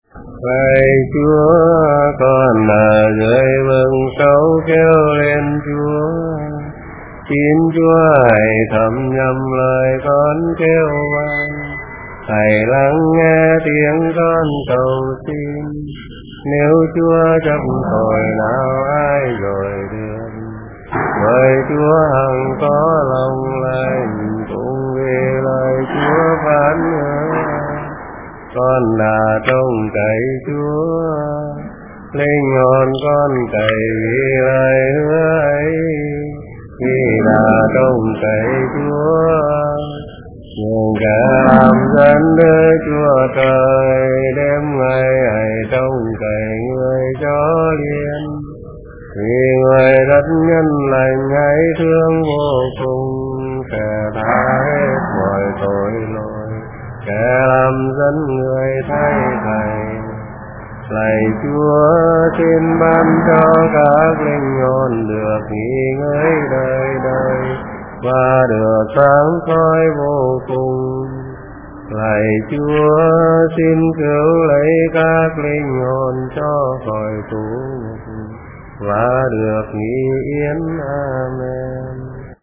Nhà em được nghe bà con giáo dân ở đó đọc kinh Vực Sâu đọc bằng giọng Nghệ An, nghe lạ tai và rất hay, em đã trở sang tận nơi, tìm người đọc chuẩn nhất để thu âm và ký âm lại. Nay em xin chia sẻ cùng cả nhà, một giọng đọc dân tộc, theo ngũ âm, rất đáng để lưu truyền.